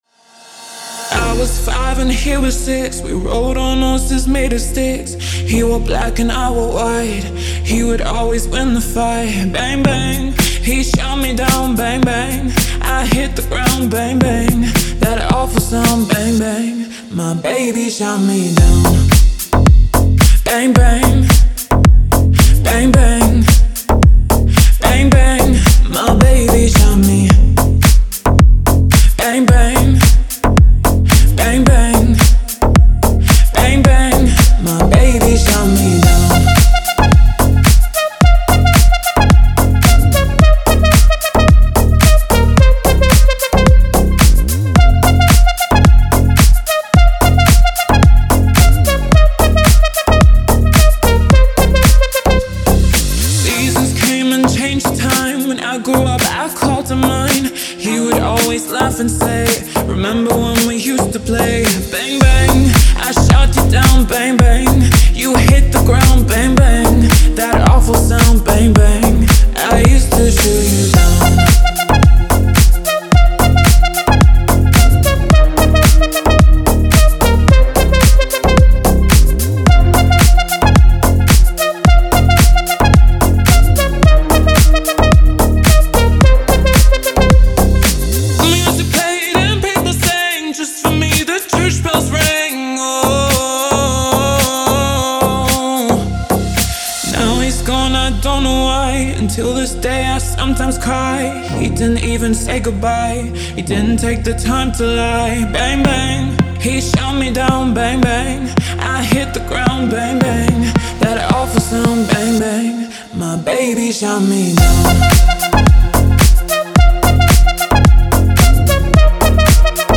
это яркая и энергичная композиция в жанре поп